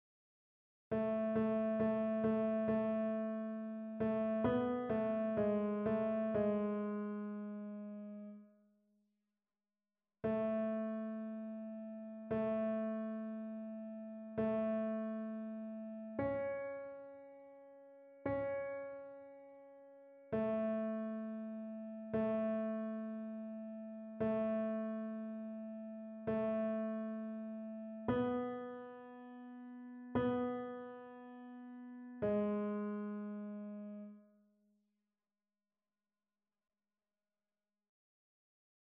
TénorBasse